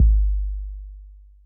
Index of /kb6/Akai_XR-20/Kick